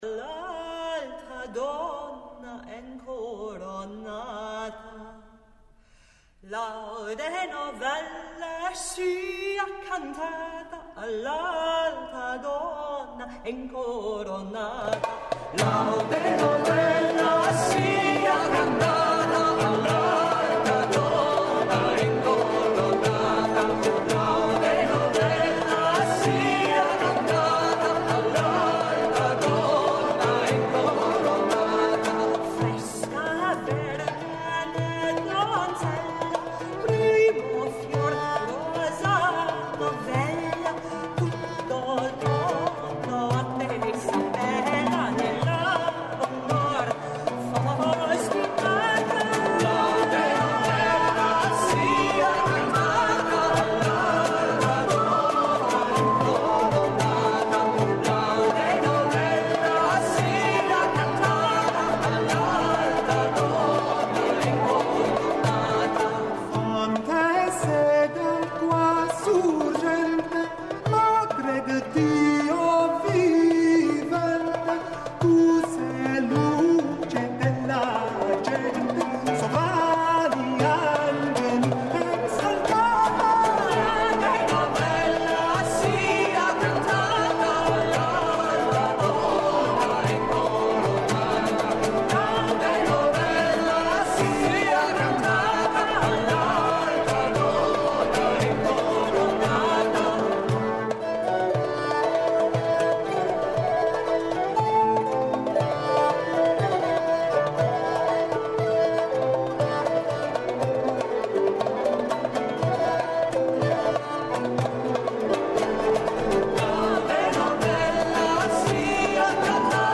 промовишу жанр познат као рана музика.